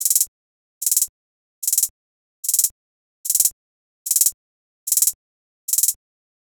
Closed Hats
HiHat (40).wav